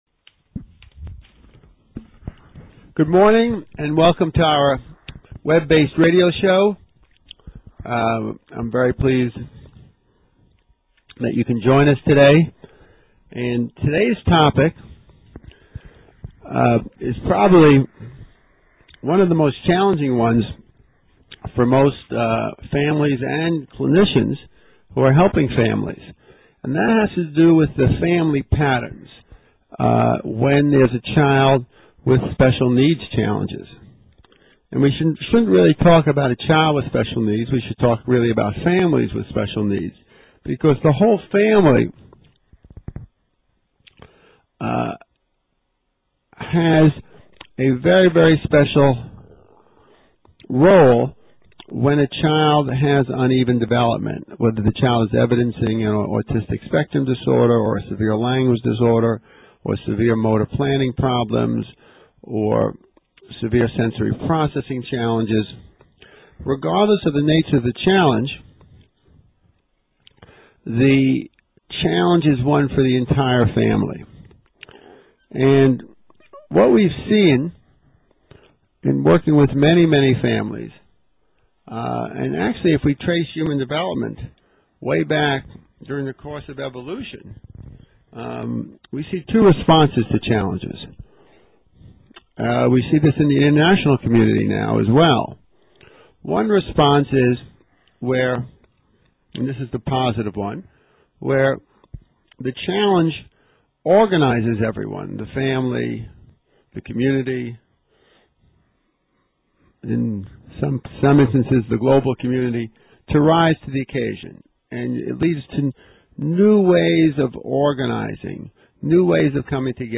Dr. Stanley Greenspan Radio Show Working with Family Dynamics: Turning Challenges into Constructive Opportunities